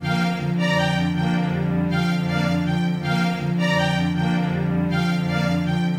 描述：是的，和其他人一样， 小提琴 大提琴 低音提琴 中提琴 拨弦乐
Tag: 145 bpm Cinematic Loops Violin Loops 2.23 MB wav Key : Unknown